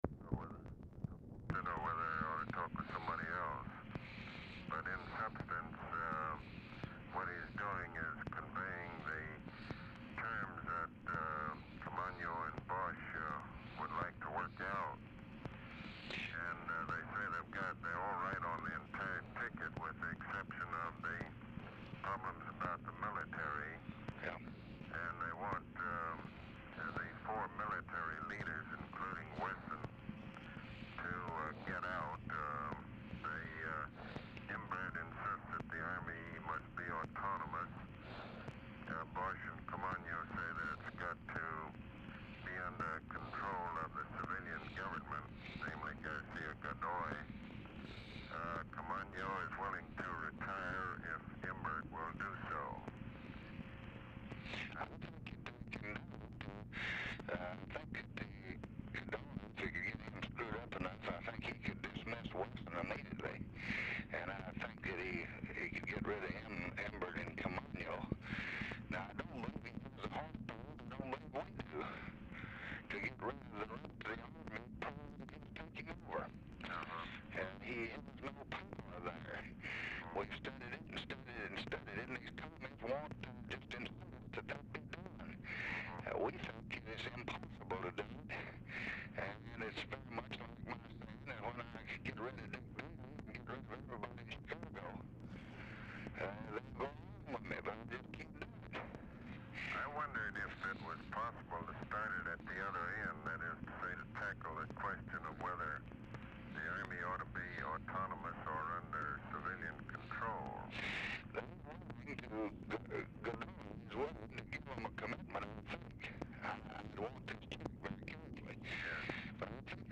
Telephone conversation # 8543, sound recording, LBJ and ABE FORTAS, 8/16/1965, 9:53AM | Discover LBJ
RECORDING STARTS AFTER CONVERSATION HAS BEGUN
POOR SOUND QUALITY
Format Dictation belt
Location Of Speaker 1 LBJ Ranch, near Stonewall, Texas
Specific Item Type Telephone conversation